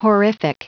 Prononciation du mot horrific en anglais (fichier audio)
Prononciation du mot : horrific